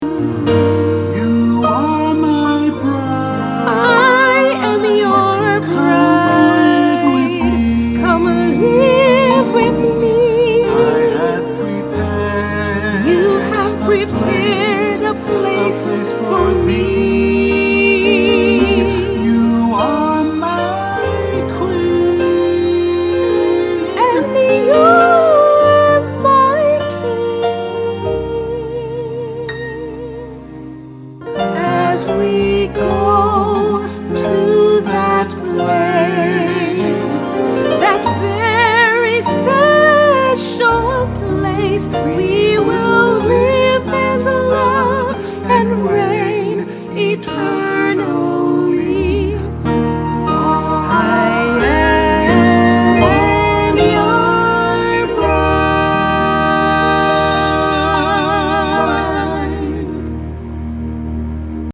Sweeten your wedding event with this new Wedding Song
this is a compelling duet